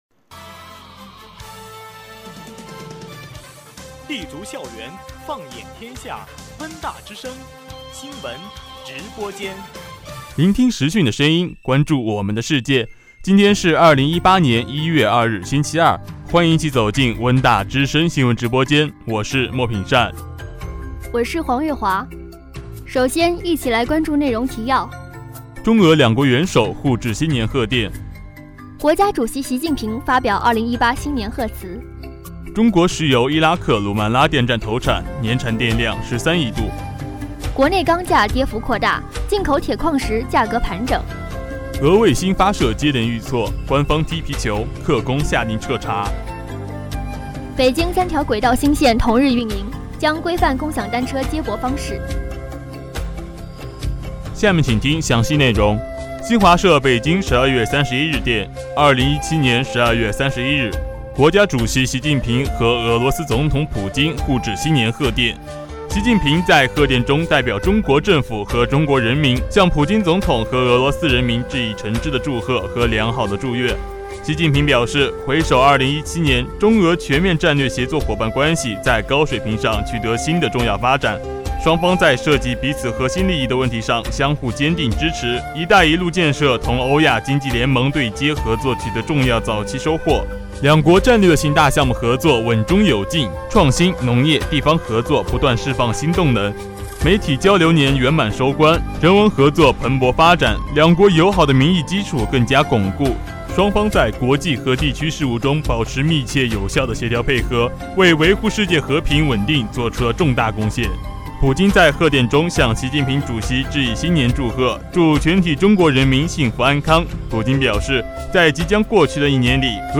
今日新闻